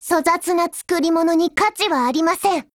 贡献 ） 协议：Copyright，其他分类： 分类:少女前线:史蒂文斯520 、 分类:语音 您不可以覆盖此文件。